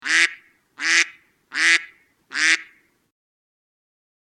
Quakende Ente klingelton kostenlos
Kategorien: Tierstimmen